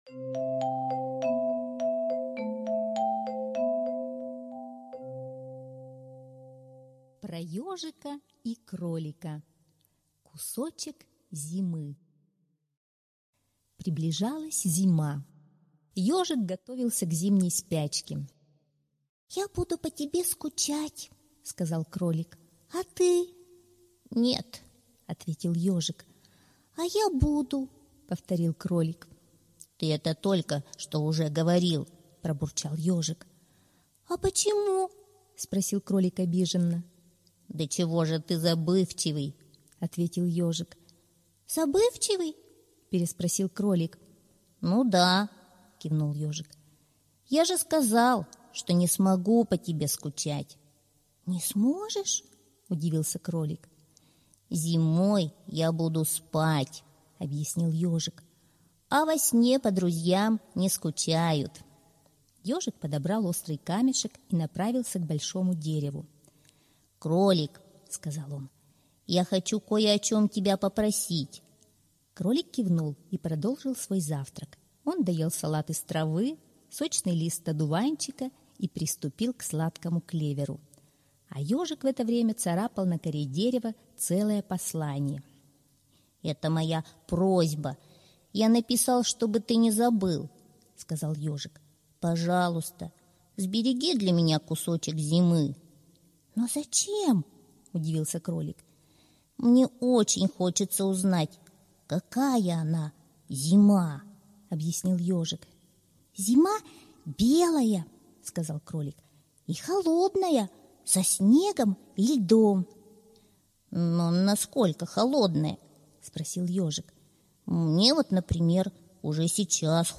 Аудиосказка «Про Ёжика и Кролика: Кусочек зимы»